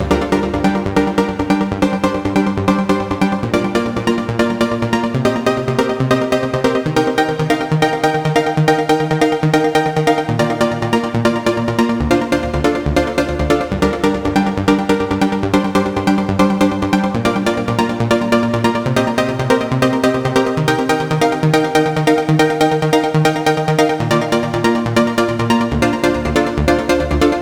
VTS1 Devastating Truth Kit 140BPM Pluck Main WET.wav